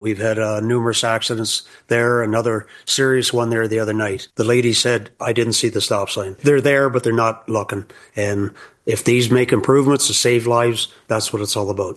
That’s due to a call for action to enhance signage and reduce accidents during Huron County Council’s meeting last Wednesday (March 6).
Deputy Mayor John Becker of Bluewater echoed Mayor Finch’s concerns, citing a recent accident at the intersection of Babylon and Crediton Road which emphasized the need for improved signage to prevent further tragedies.
march-11-hc-council-meeting-stop-signs-becker.mp3